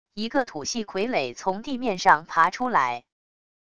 一个土系傀儡从地面上爬出来wav音频